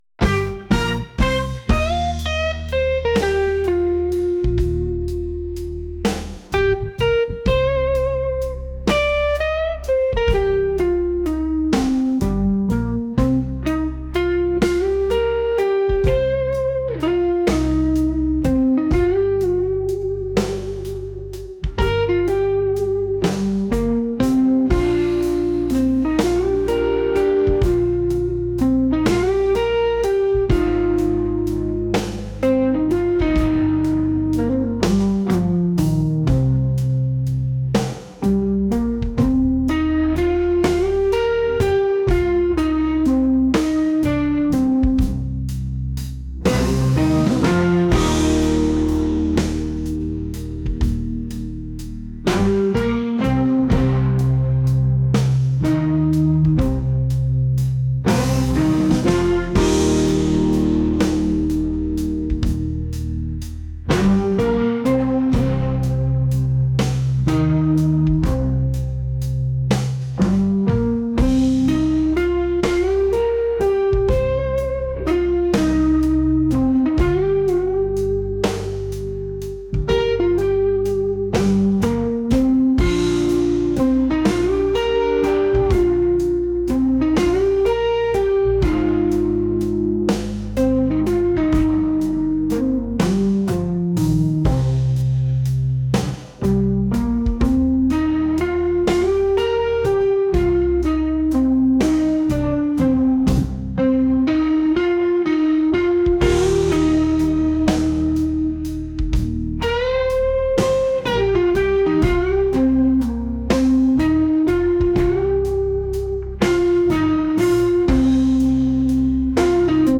blues | soul & rnb